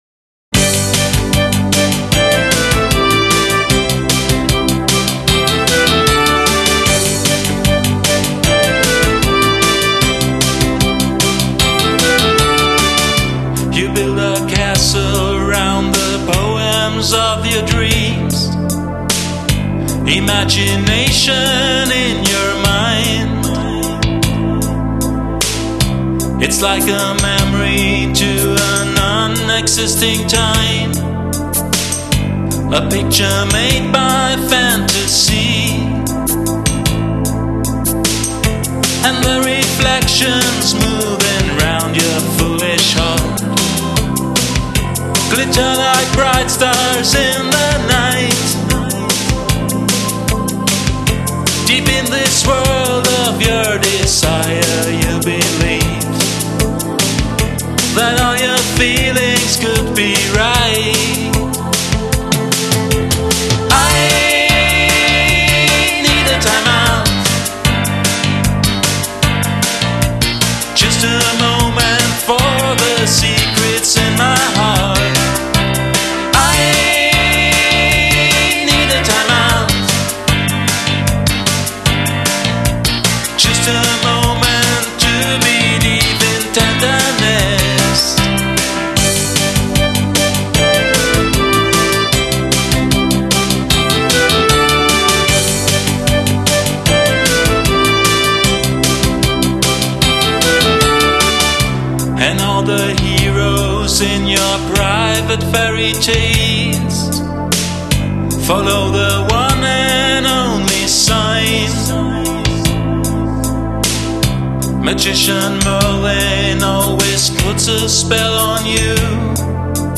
Keyboards
Gesang